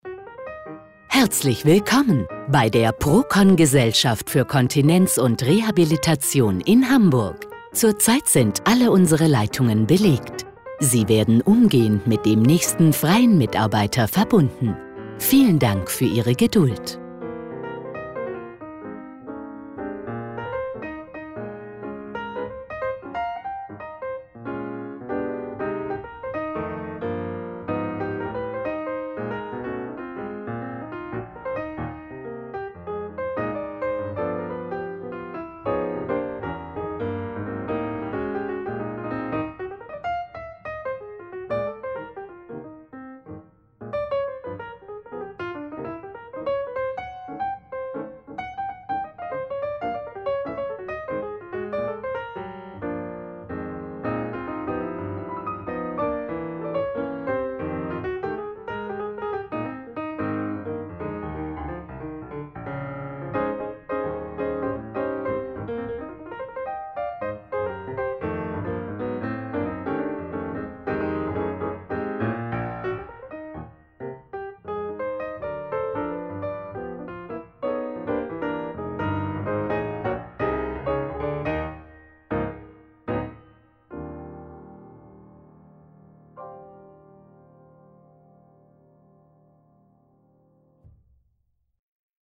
Ansage vor Melden:
Procon-Ansage-vor-Melden-1a.mp3